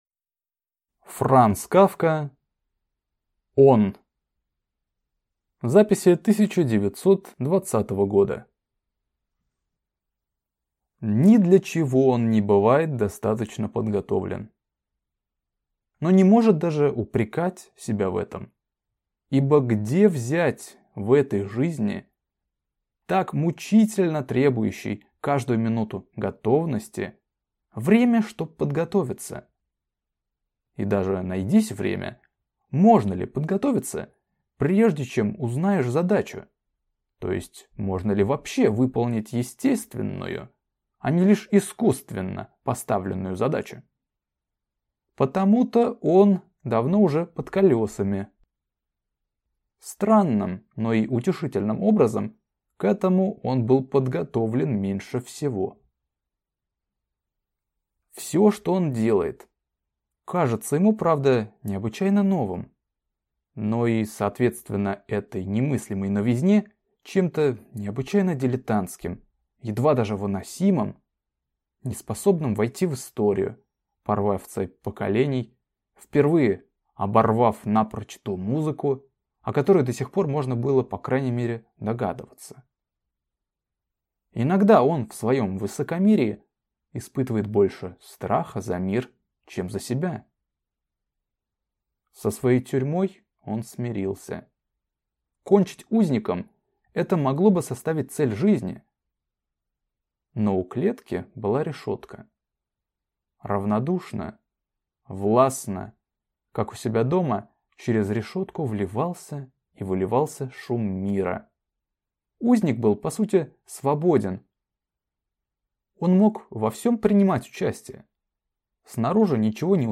Аудиокнига Он. Записи 1920 года | Библиотека аудиокниг